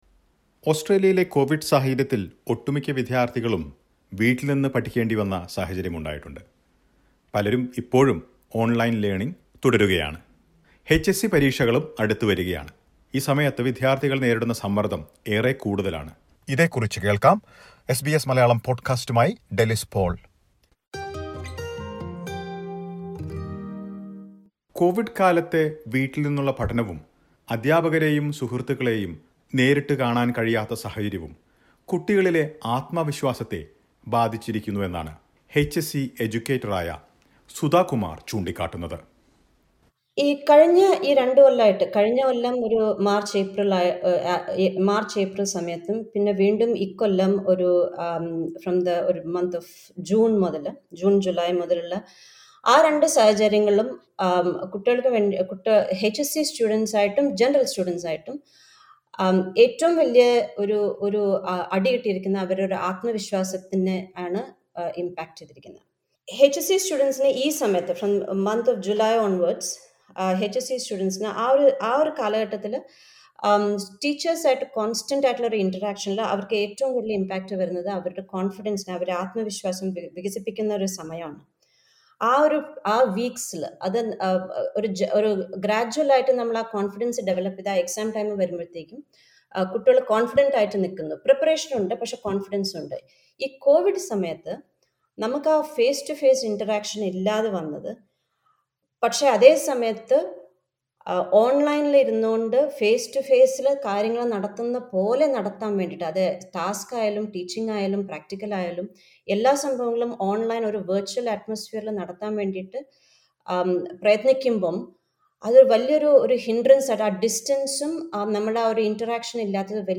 As HSC exams are fast approaching many students are anxious about the future during these uncertain times. Listen to a report.